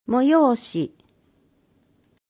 文字を読むことが困難な視覚障害者や高齢者や、市報を聞きたい方のために、「魚沼音声訳の会」のご協力により市報うおぬま音声版（ＭＰ3）をお届けします。